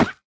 Minecraft Version Minecraft Version latest Latest Release | Latest Snapshot latest / assets / minecraft / sounds / mob / armadillo / hurt_reduced1.ogg Compare With Compare With Latest Release | Latest Snapshot
hurt_reduced1.ogg